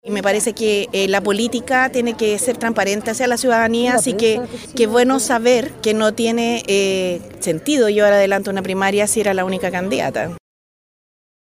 Durante esta mañana, la candidata presidencial del Partido Comunista, Jeannette Jara, afirmó que no entendía como la derecha pretendía darle gobernabilidad al país si no se podrían poner de acuerdo entre ellos.